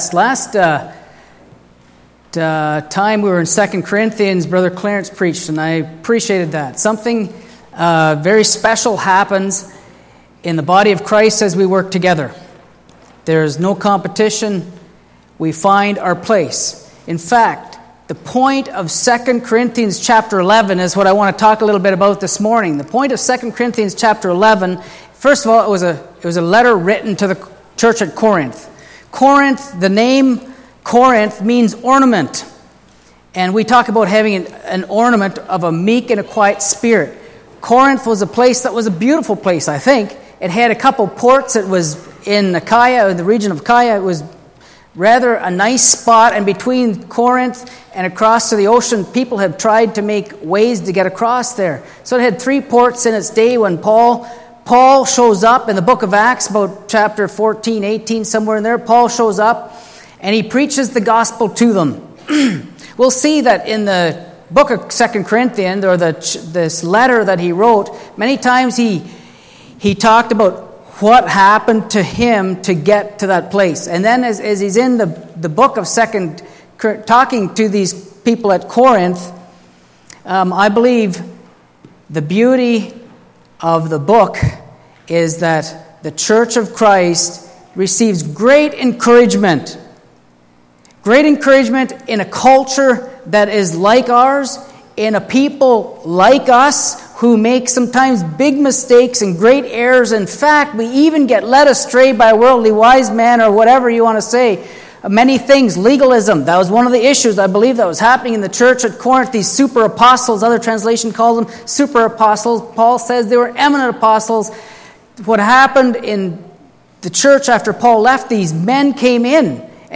Series: Sunday Morning Sermon